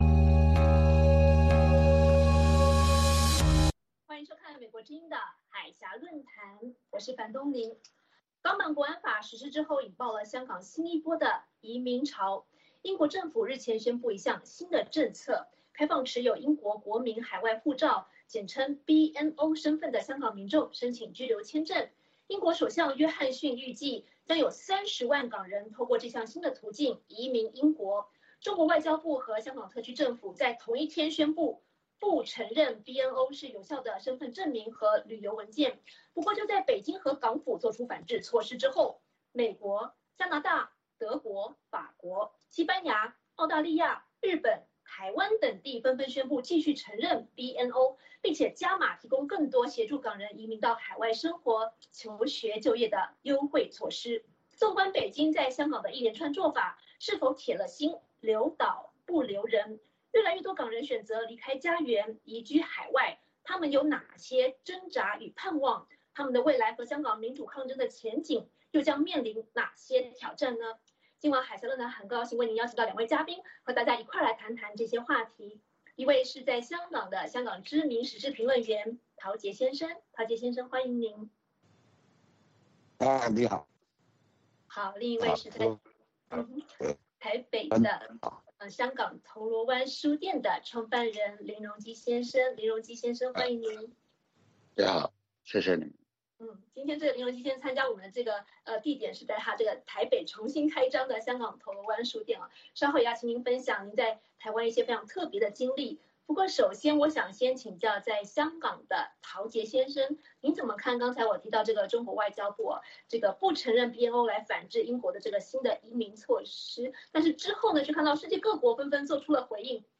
《海峡论谈》节目邀请华盛顿和台北专家学者现场讨论政治、经济等各种两岸最新热门话题。